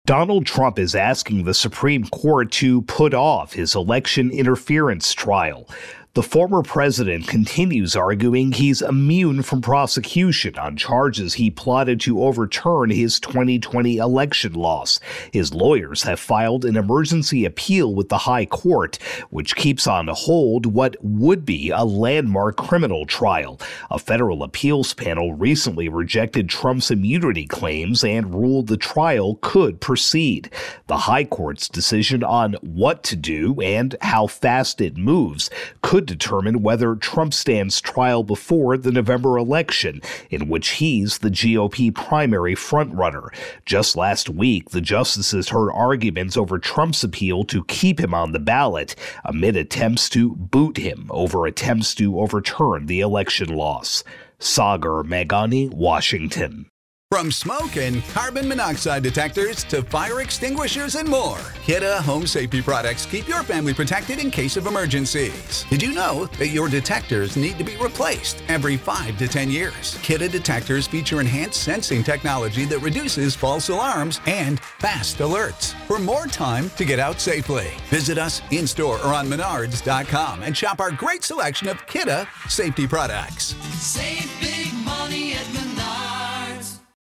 reports on Trump Capitol Riot.